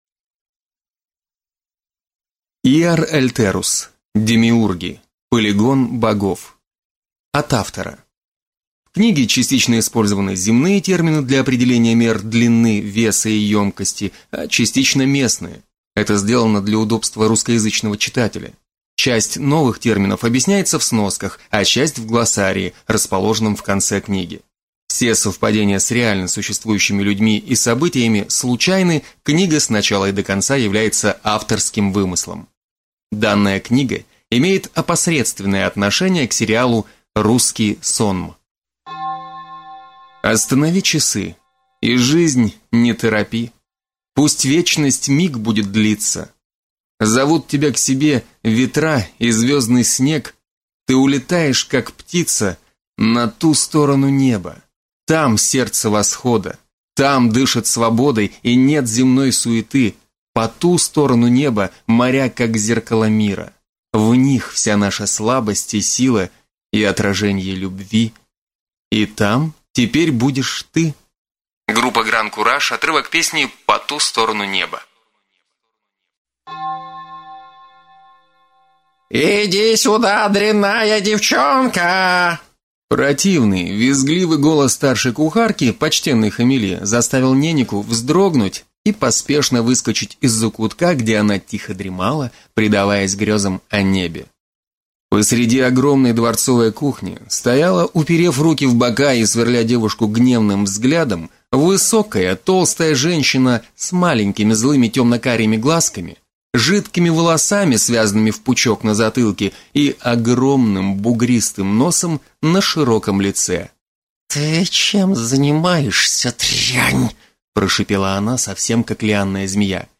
Аудиокнига Демиурги. Полигон богов | Библиотека аудиокниг